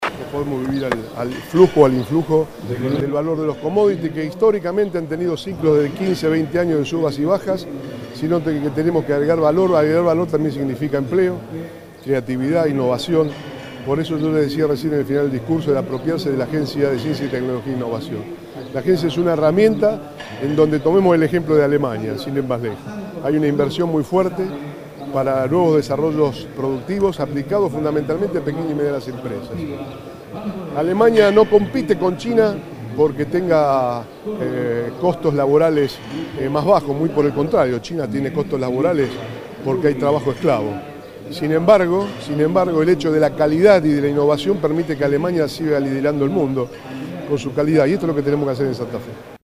Bonfatti participó en Casilda de la celebración del Día de la Industria
Declaraciones de Bonfatti.